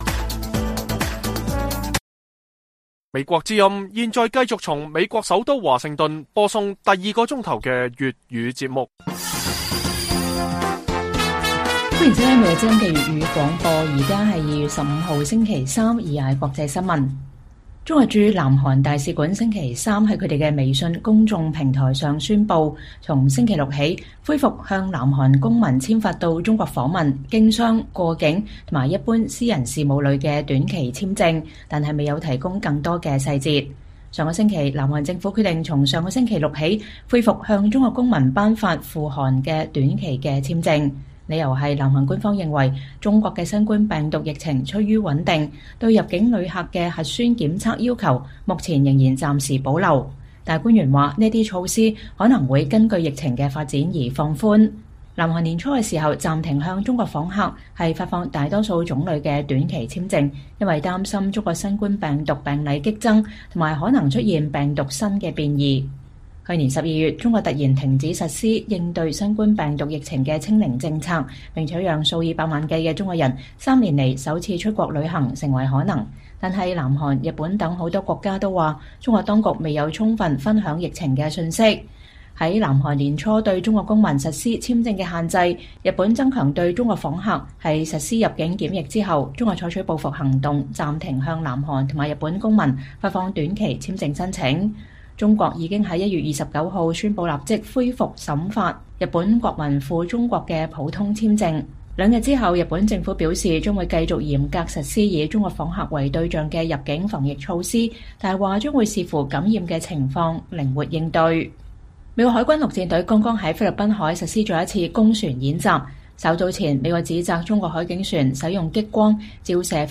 粵語新聞 晚上10-11點: 中國宣布恢復向南韓公民頒發短期簽證